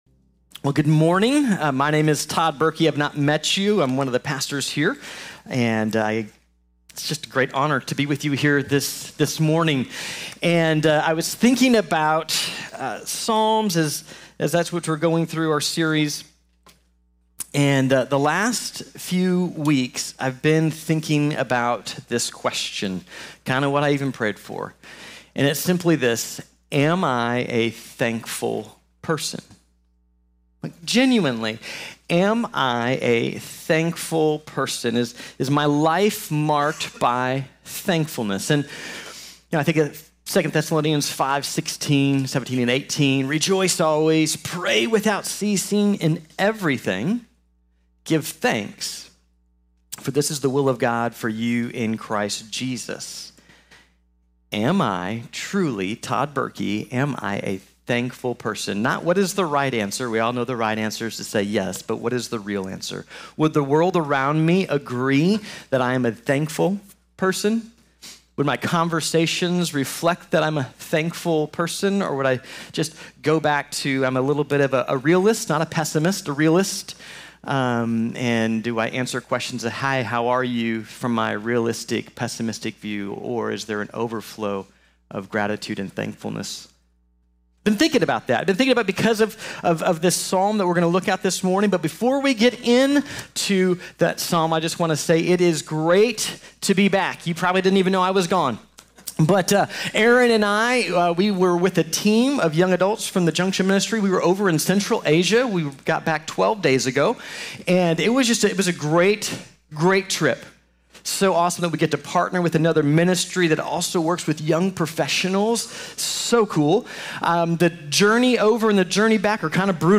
Consider God's Hesed | Sermon | Grace Bible Church